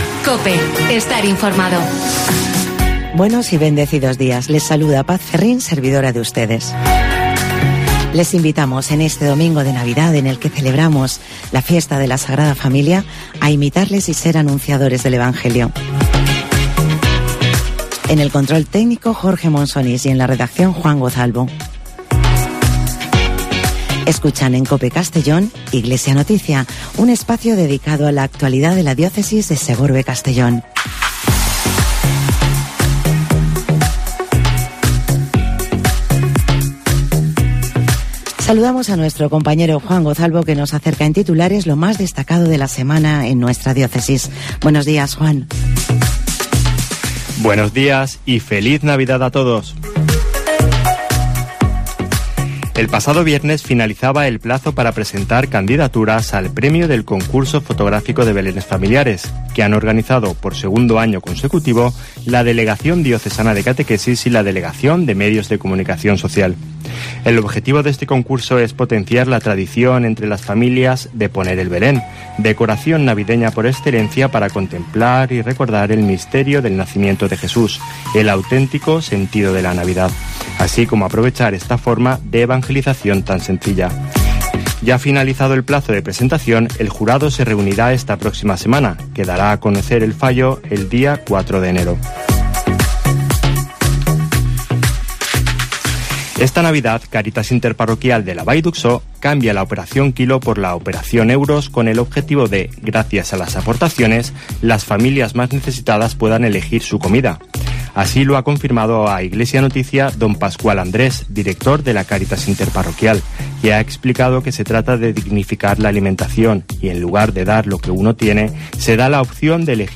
AUDIO: Espacio informativo de la Diócesis de Segorbe-Castellón